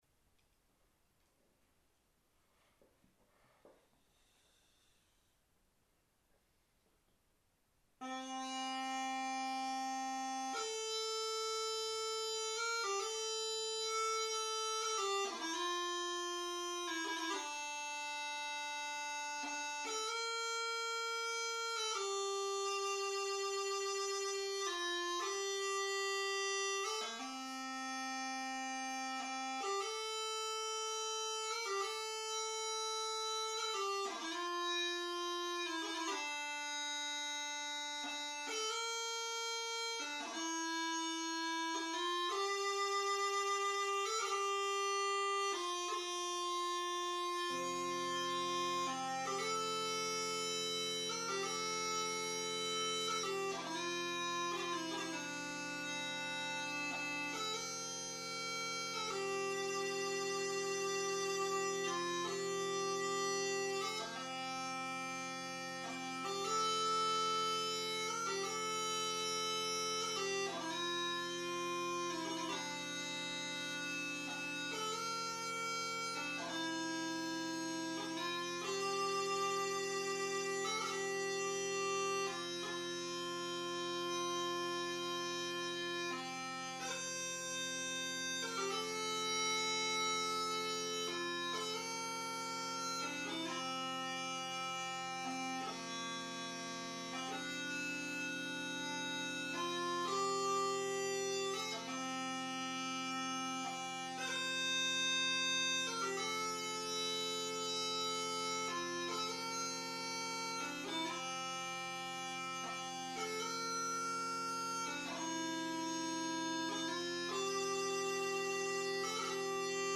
Here are some sound samples of different woods and keys.
Blackwood pipes in C